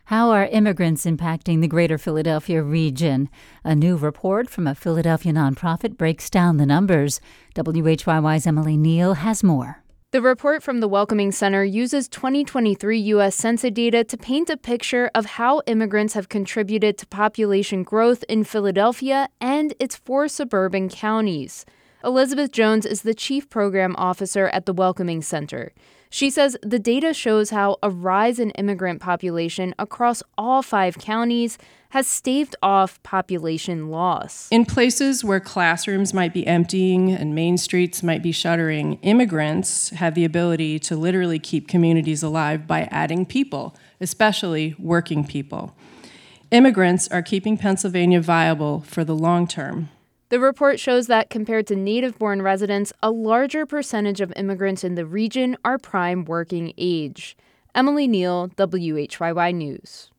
This hour, we listen to most of that interview.